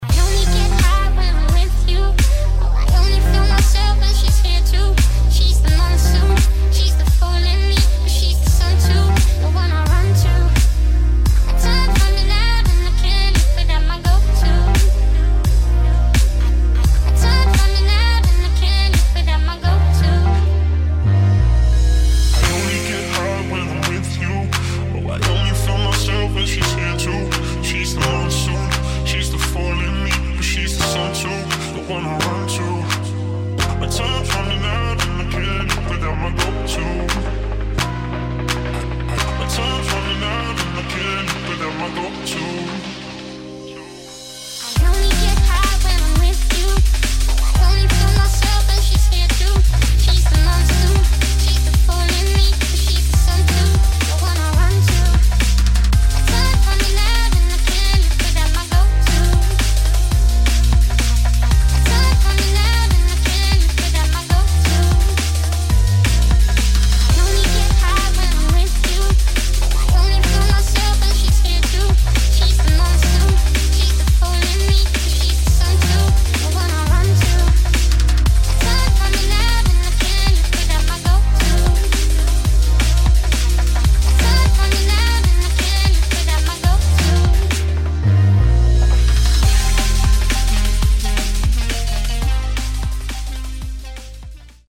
[ DRUM'N'BASS / POP / DUBSTEP ]